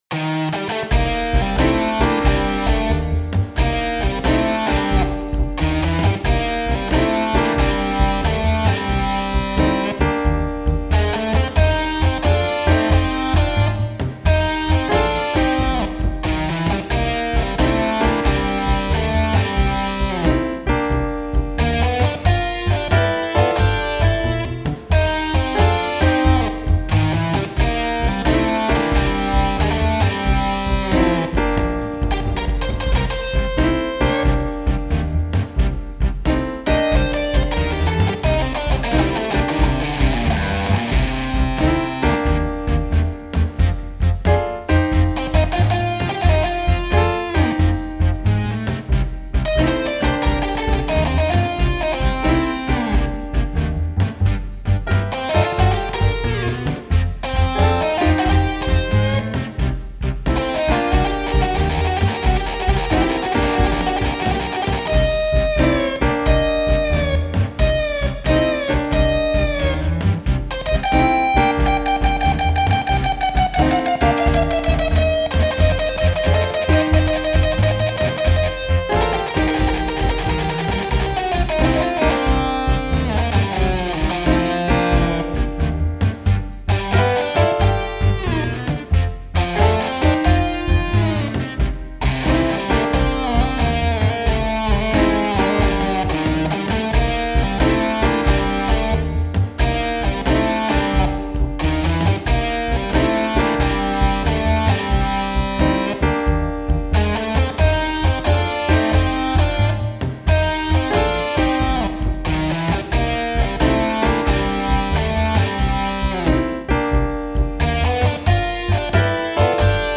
Roland Fantom XR Version